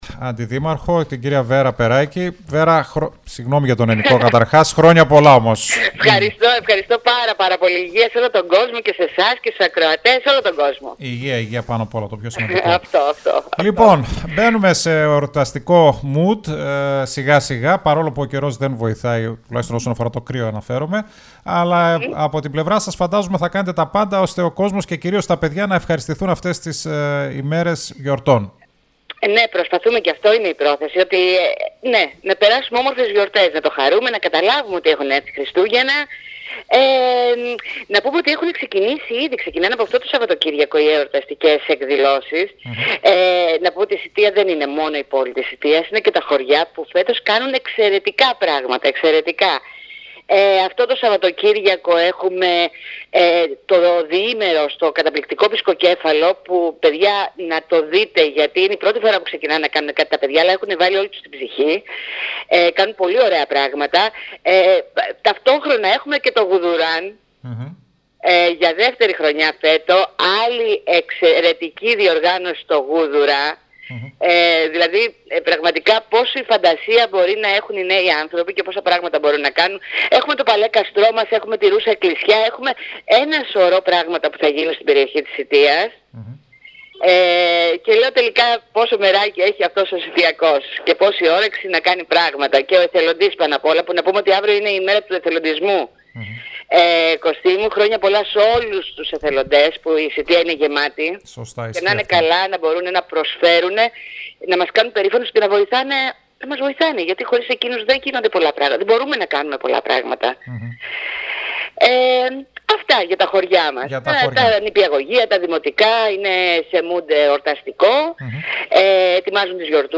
Βέρα Περράκη Αντιδήμαρχος Πολιτισμού στον STYLE 100: Χριστούγεννα στα χωριά του Δήμου Σητείας